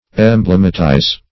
Search Result for " emblematize" : The Collaborative International Dictionary of English v.0.48: Emblematize \Em*blem"a*tize\, v. t. [imp.